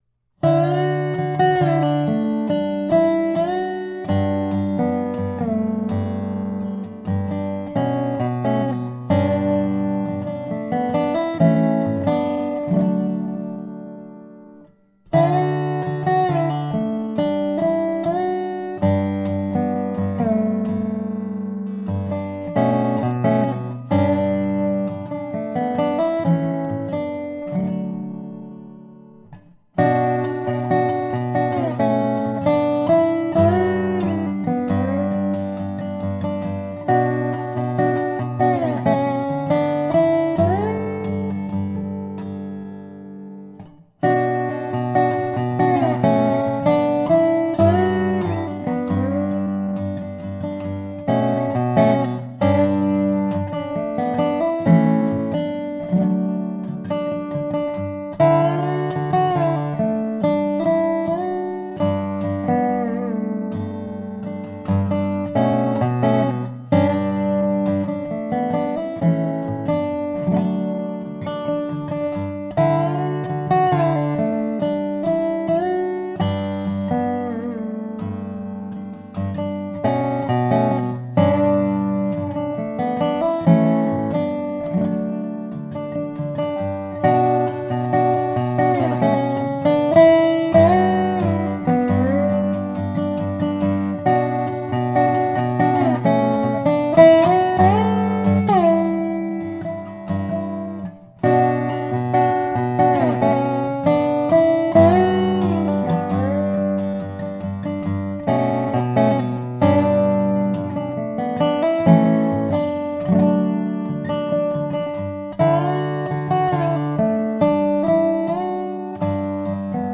Solo Dobro Part 1
Here This Morning slightly slower.mp3